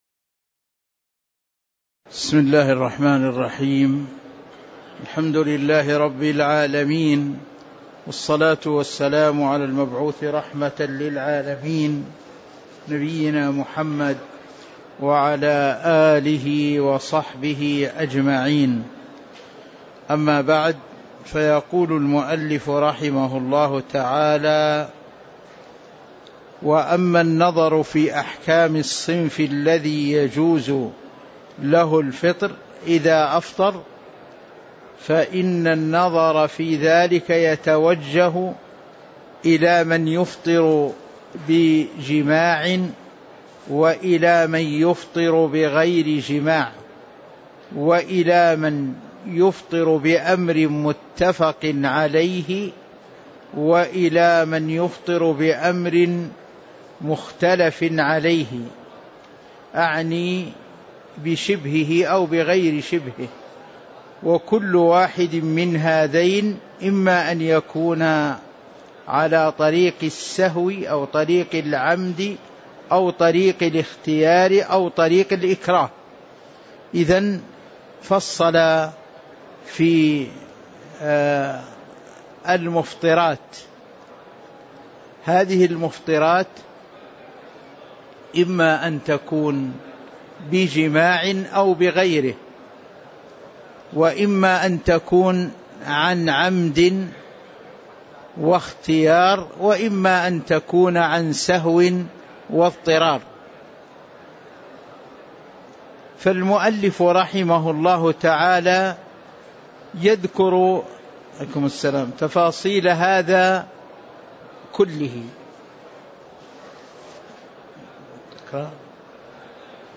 تاريخ النشر ١٤ جمادى الآخرة ١٤٤٦ هـ المكان: المسجد النبوي الشيخ